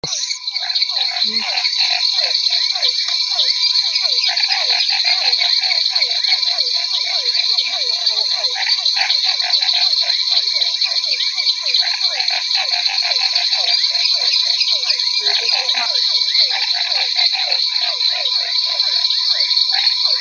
Ranita Trepadora Amarilla (Dendropsophus minutus)
junto a P. cuvieri, B. raniceps, L. fuscus
Localidad o área protegida: Reserva Privada San Sebastián de la Selva
Condición: Silvestre
Certeza: Fotografiada, Vocalización Grabada
Dendropsophus-minutus.mp3